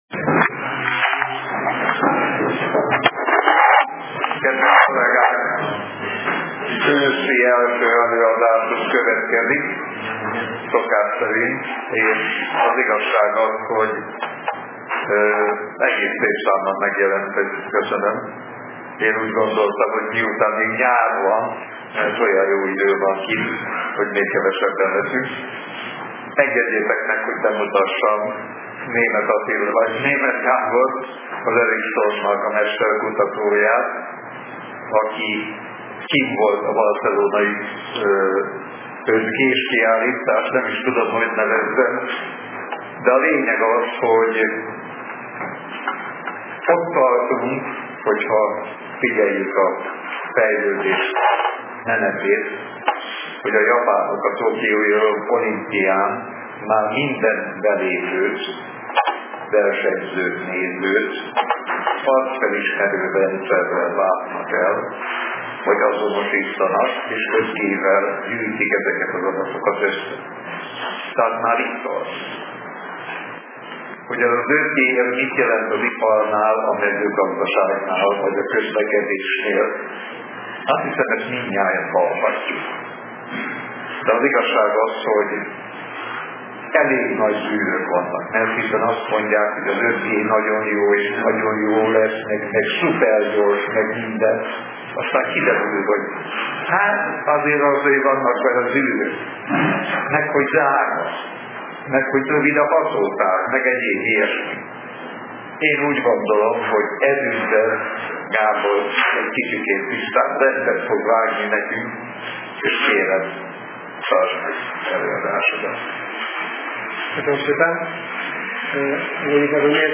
A Vételtechnikai és a Kábeltelevíziós Szakosztály, valamint a Médiaklub meghívja az érdeklődőket az alábbi érdekes és aktuális előadásra: